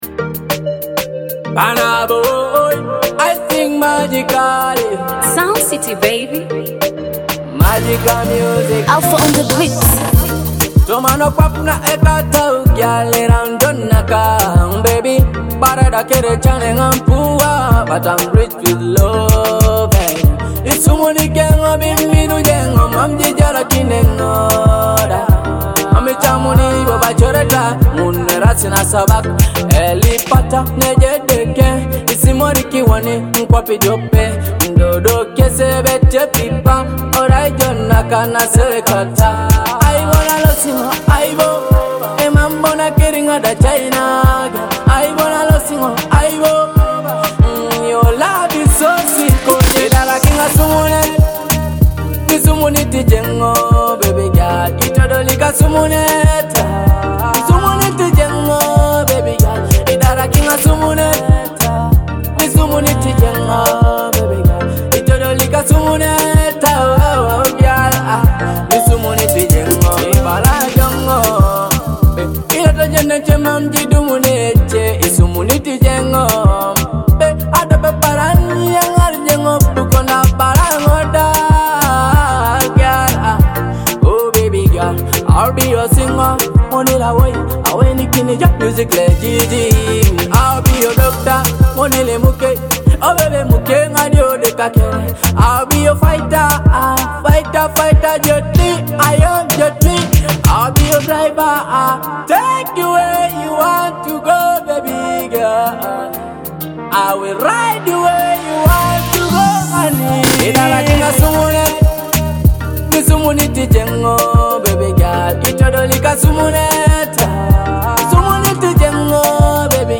a track that blends catchy melodies with heartfelt lyrics.